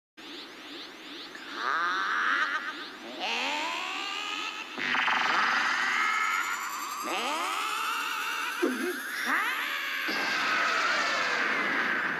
kamekameha_sonidosemananubosadraonball_abril24.mp3